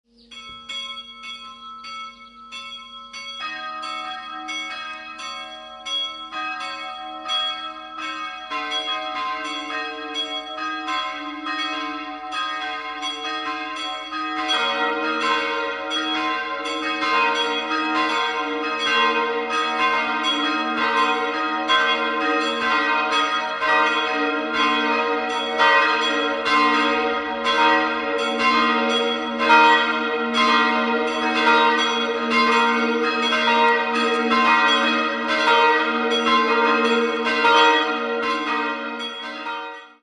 Friedrich Wilhelm Schilling goss 1965 die Glocken b’ (421kg) und es’’ (163kg). Die anderen beiden stammen von Hanns Pfeffer, Nürnberg (Gussjahr 1617) und Christian Viktor Herold, Nürnberg (Gussjahr 1758).